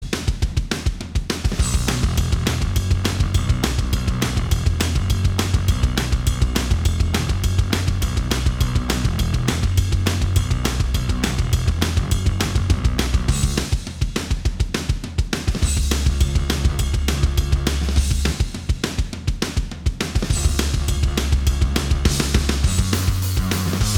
Minus All Guitars Soundtracks 2:19 Buy £1.50